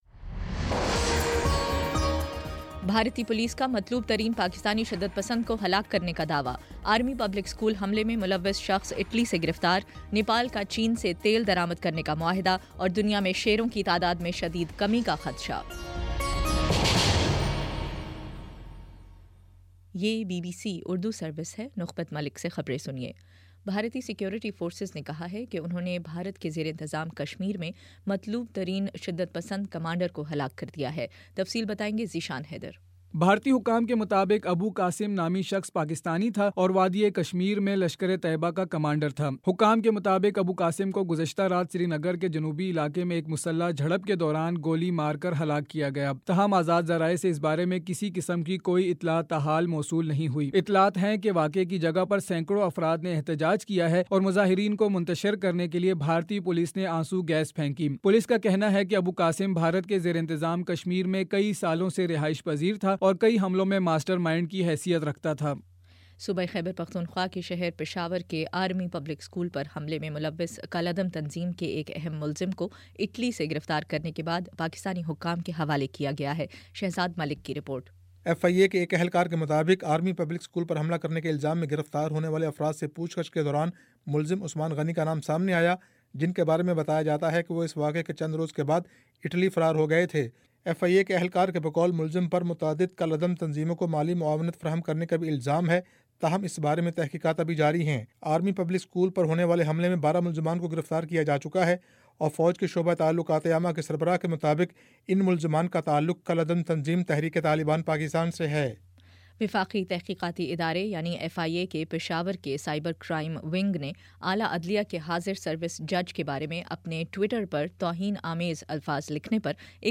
اکتوبر 29 : شام پانچ بجے کا نیوز بُلیٹن